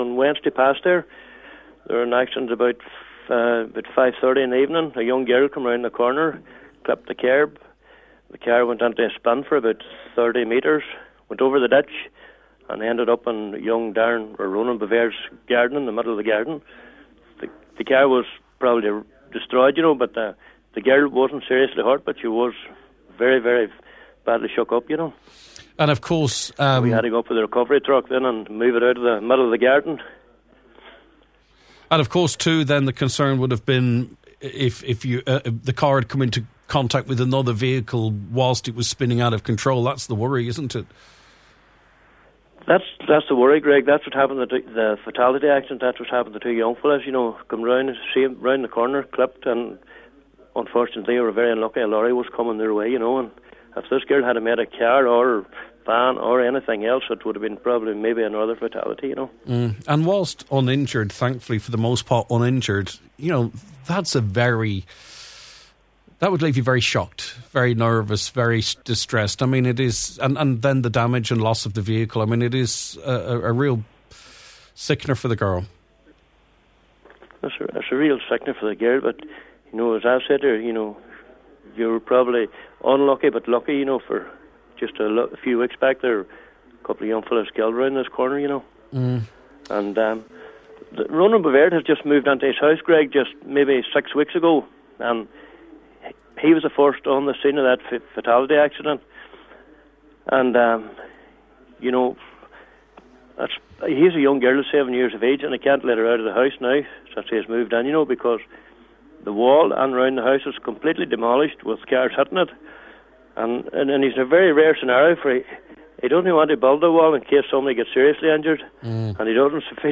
full discussion